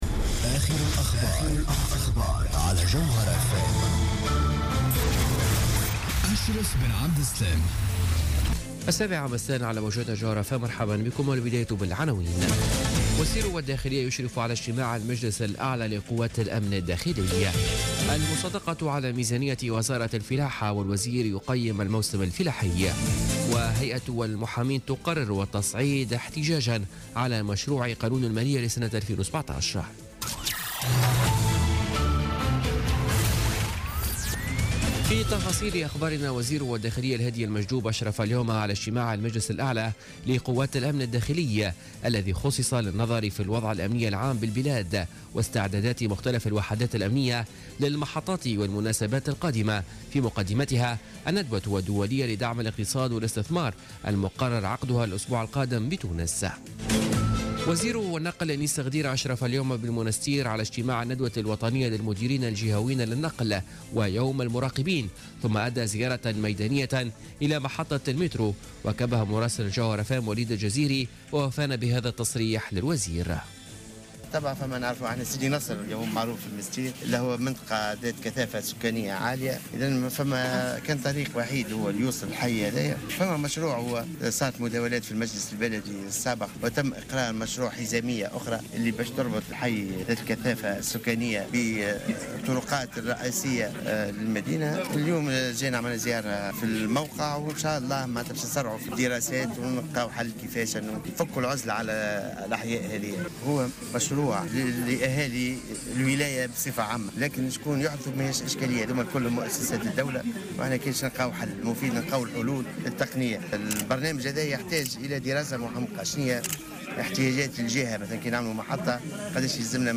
نشرة أخبار السابعة مساء ليوم السبت 26 نوفمبر 2016